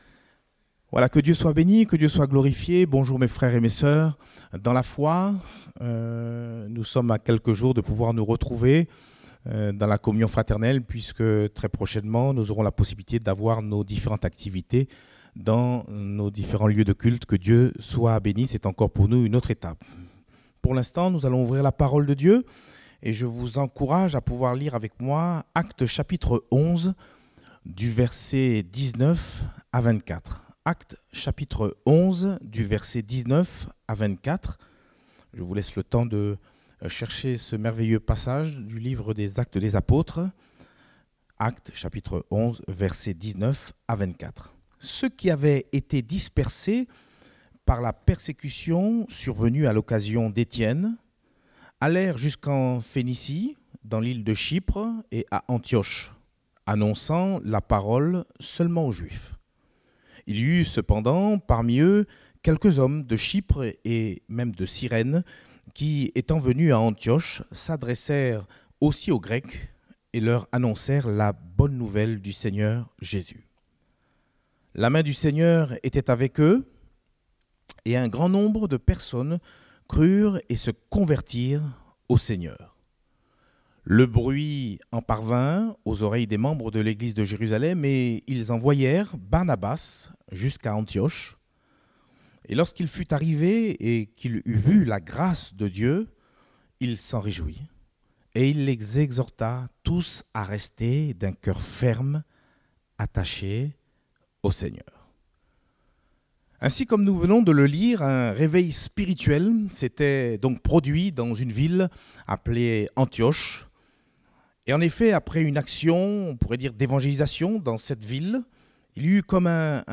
Type De Service: Culte Dominical